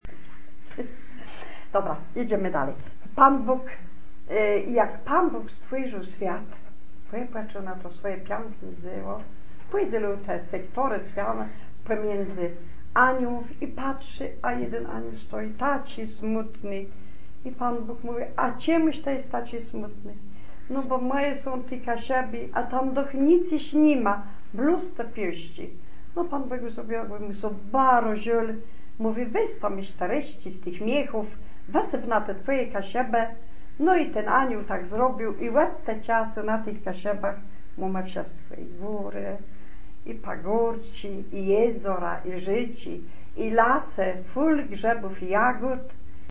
Kaszuby środkowe